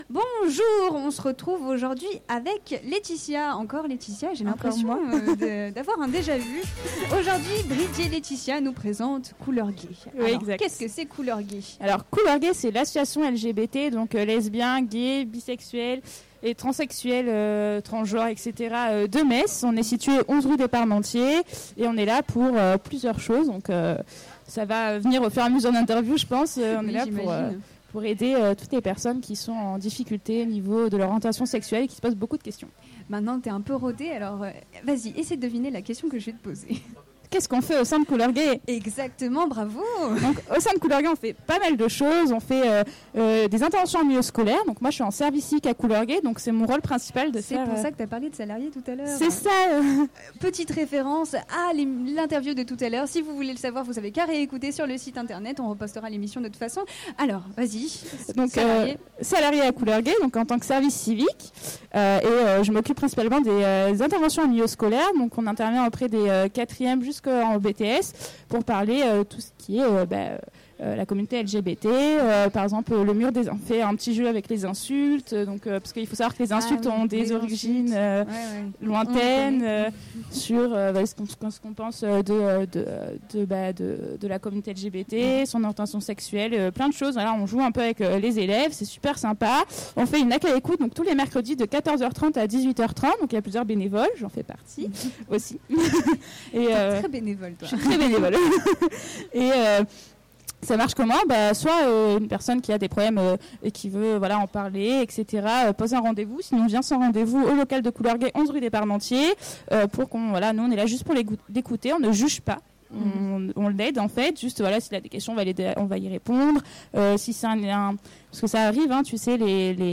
Le dernier week-end de septembre a eu lieu la journée de clôture d’Etudiant dans ma ville à Metz, et l’équipe de Radio Campus Lorraine a bravé la pluie Place de la République pour vous faire découvrir les différentes associations qui animaient cet événement.